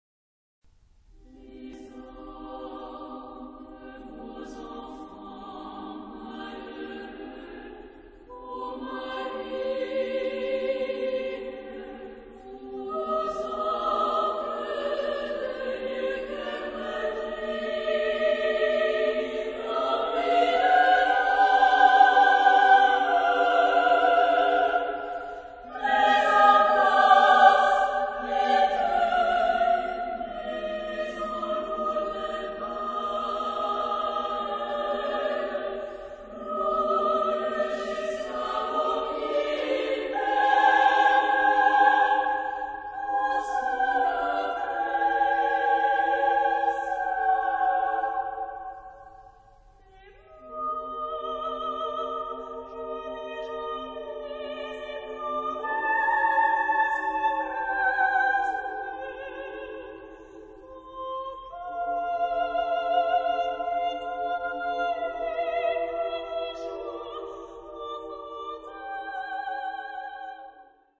Género/Estilo/Forma: Coro ; Sagrado ; contemporáneo
Tonalidad : do mayor